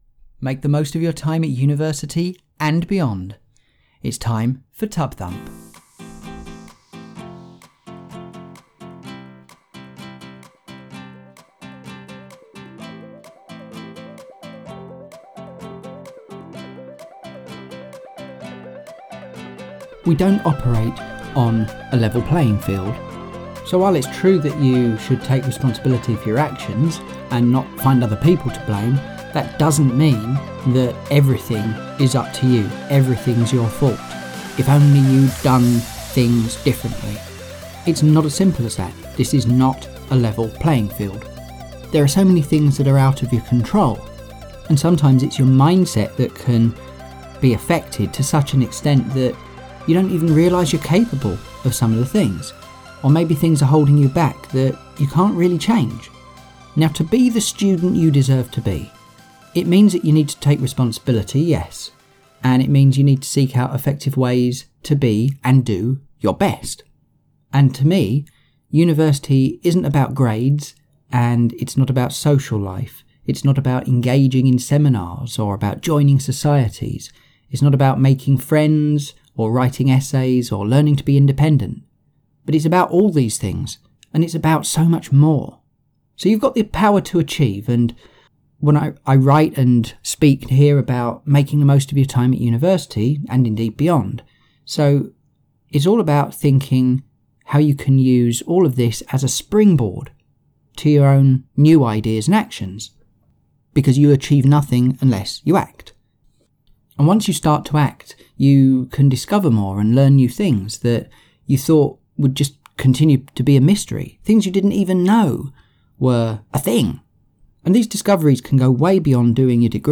Music for TUB-Thump is Life, by Tobu, which is released under a Creative Commons license.